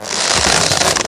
Bow4.ogg